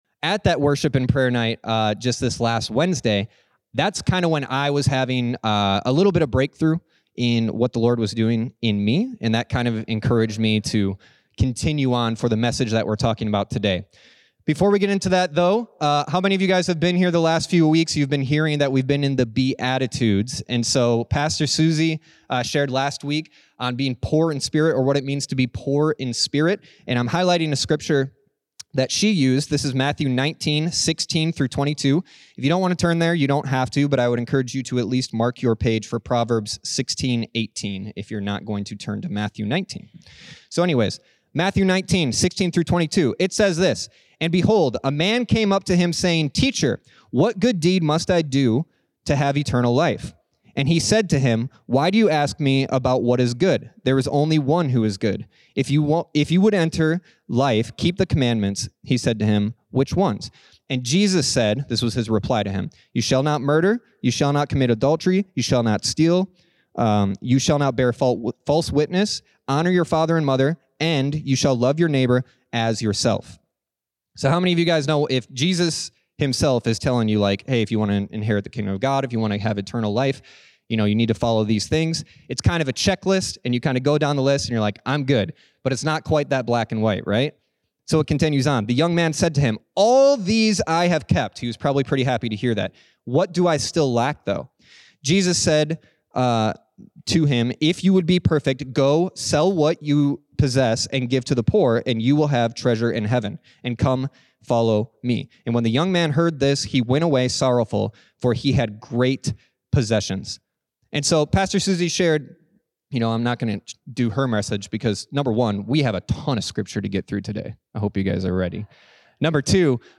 Enjoy these stand-alone messages from Dwelling House Church.